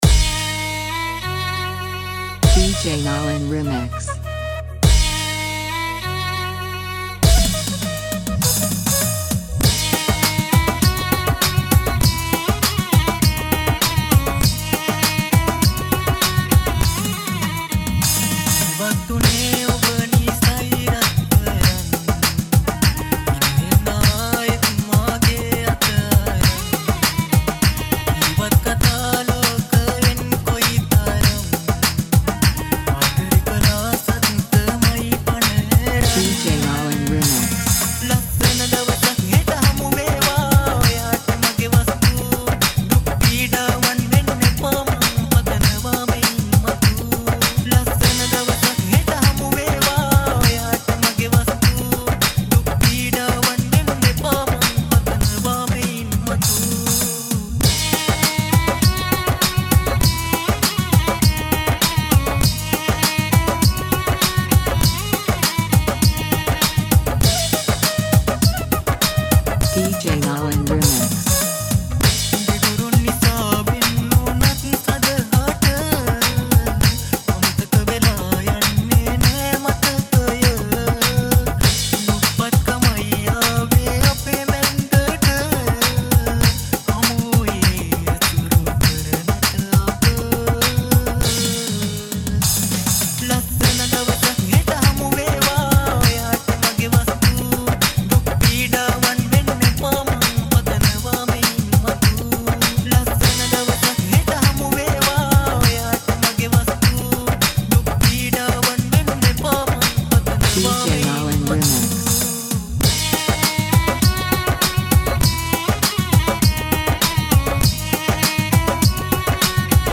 High quality Sri Lankan remix MP3 (3.6).